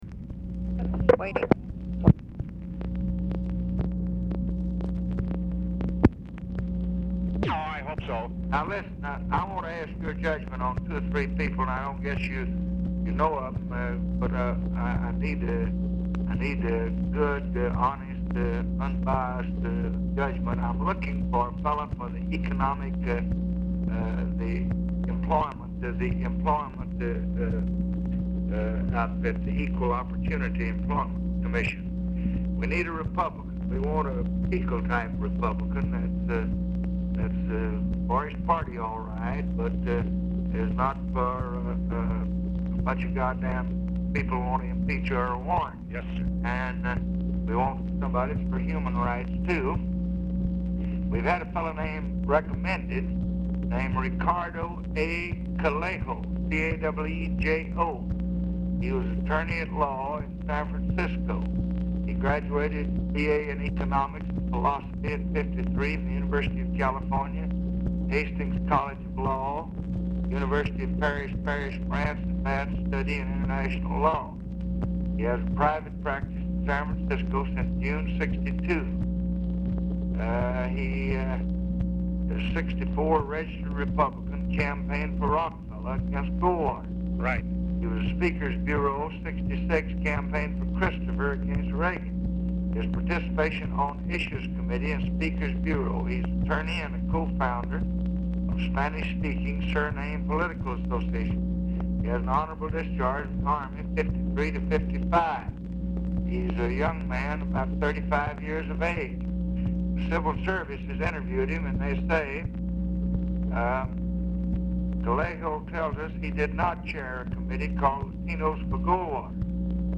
Telephone conversation
POOR SOUND QUALITY; RECORDING STARTS AFTER CONVERSATION HAS BEGUN AND ENDS BEFORE IT IS OVER; RECORDING IS INTERRUPTED BY TELEPHONE OPERATOR'S CALL TO OFFICE SECRETARY
Dictation belt